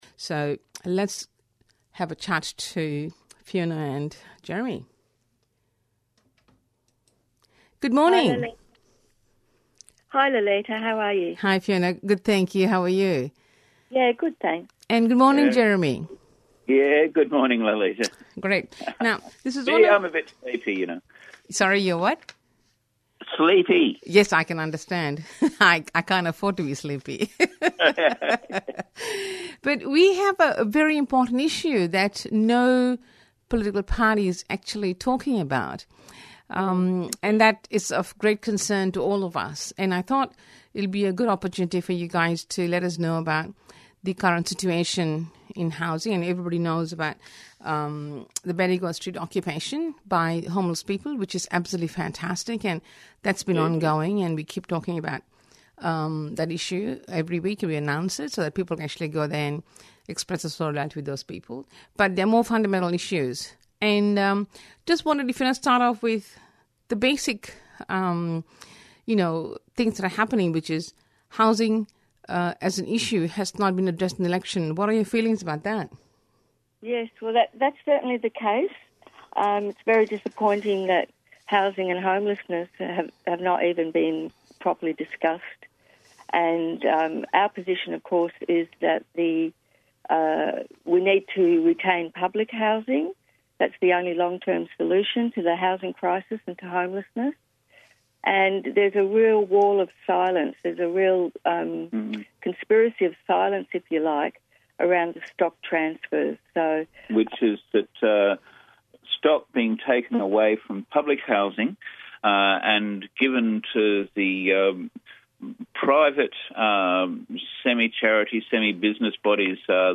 Interviews with Friends Of Public Housing, Australian Palestinian Advocacy Network, and The West Papua movement of Australia.